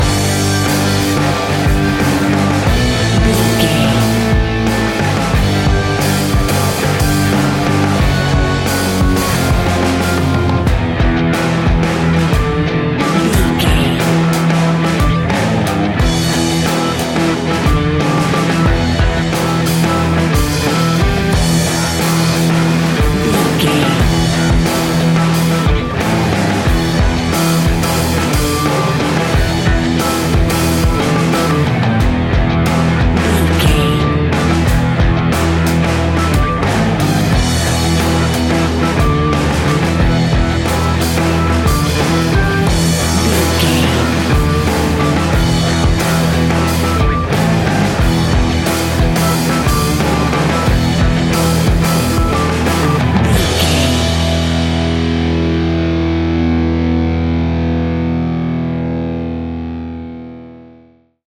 Ionian/Major
hard rock
heavy rock
distortion
instrumentals